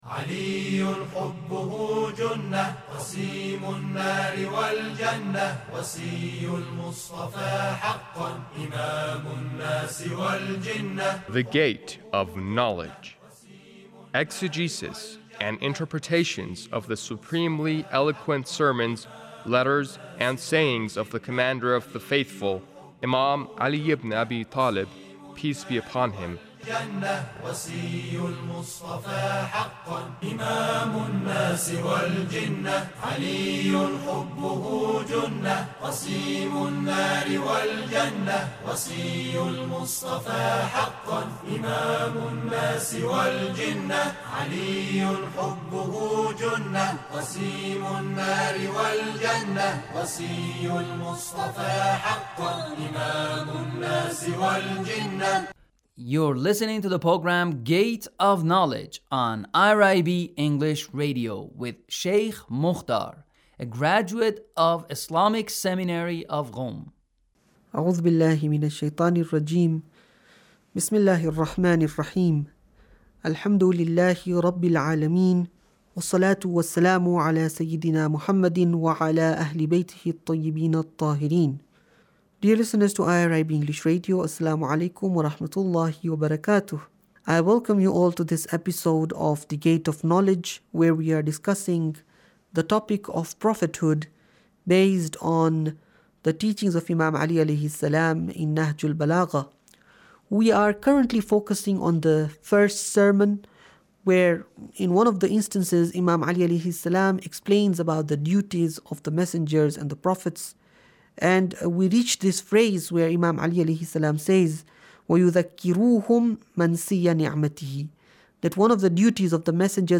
Sermon 1 - Prophethood 2